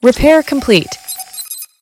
repaircomplete.ogg